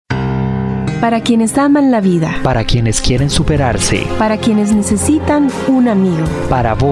Rodrigo Arias Camacho, Rector de la UNED